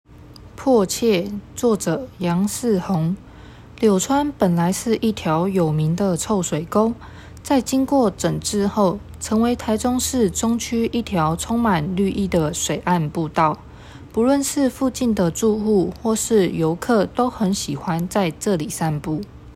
語音導覽，另開新視窗